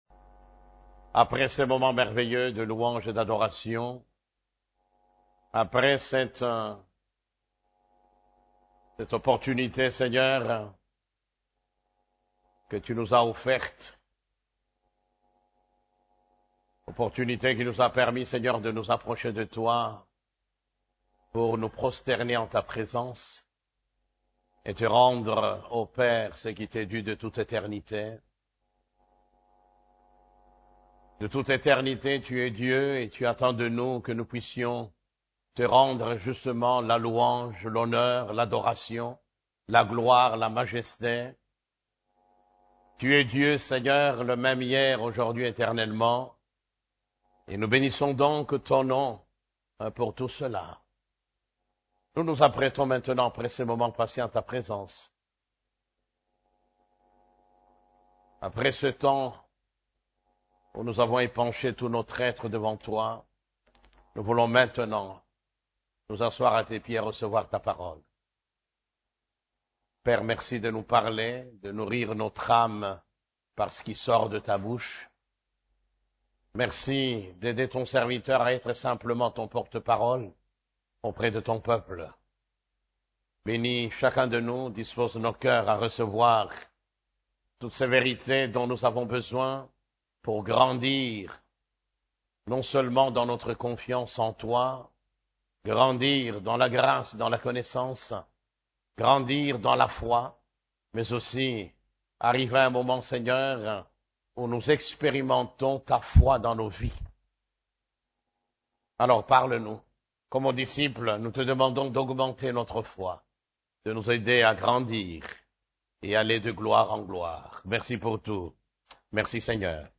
CEF la Borne, Culte du Dimanche, Comment voir l'invisible ? (6)